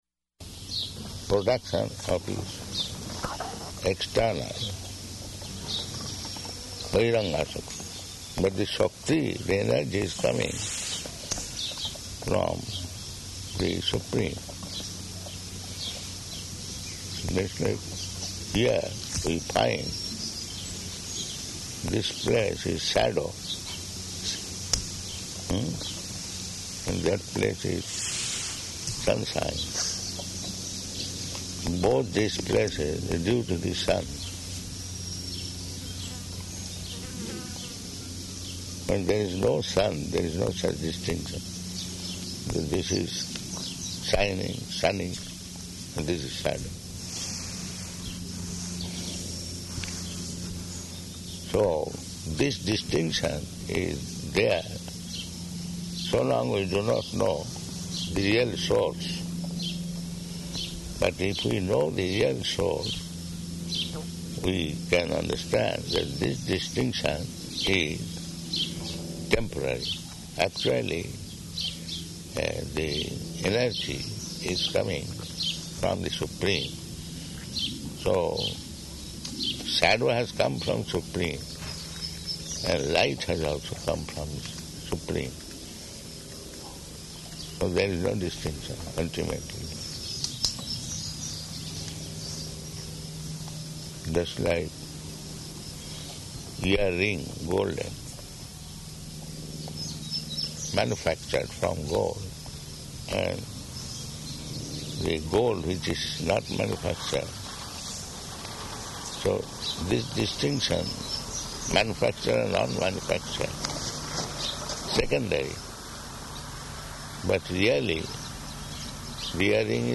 Garden Conversation
Garden Conversation --:-- --:-- Type: Conversation Dated: June 23rd 1976 Location: New Vrindavan Audio file: 760623GC.NV.mp3 Prabhupāda: ...production of it's external.